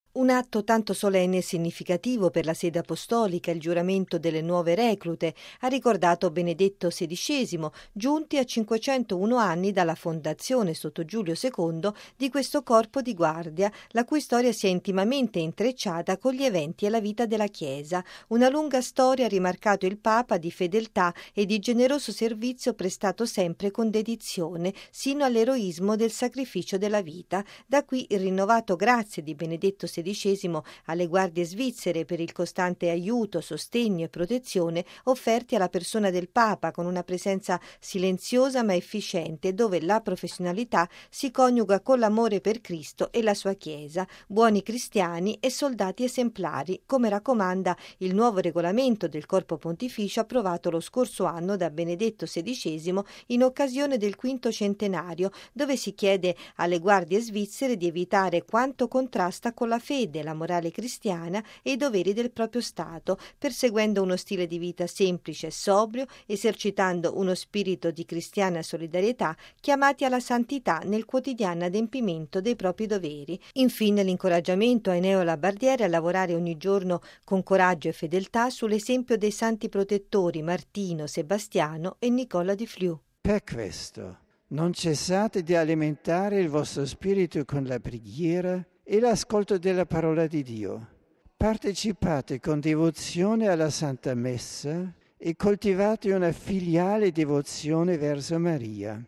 ◊   Una lunga storia di fede ed amore che dura da cinque secoli, quella delle Guardie Svizzere Pontificie, che Benedetto XVI ha ricevuto stamane con i loro familiari nella Sala Clementina, in occasione del Giuramento delle 38 nuove reclute, che avverrà domani pomeriggio nel Cortile di San Damaso, nel giorno della memoria dei 147 caduti a difesa del Papa Clemente VII, durante il Sacco di Roma, il 6 maggio del 1527.